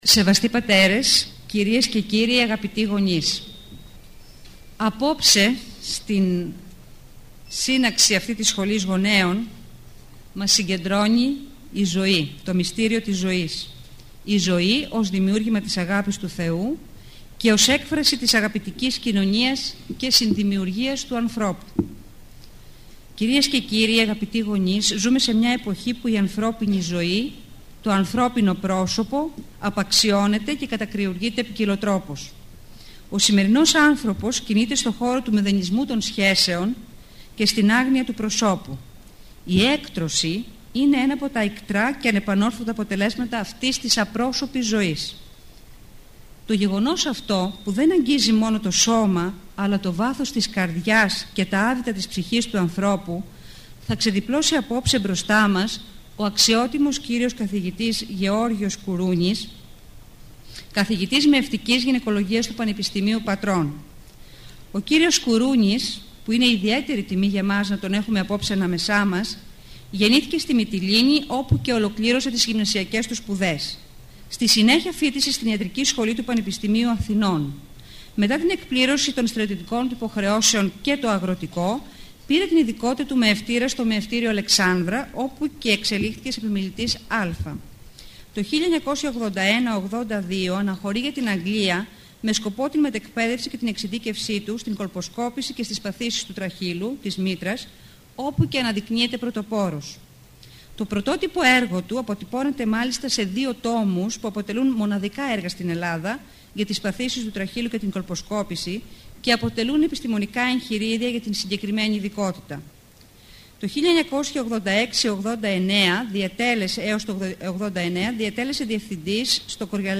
Η ομιλία αυτή “πραγματοποιήθηκε” στα πλαίσια των εκδηλώσεων της σχολής γονέων της Ιεράς Μητροπόλεως Αιτωλίας και Ακαρνανίας, στο Αγρίνιο, στις 09 Οκτωβρίου του 2011.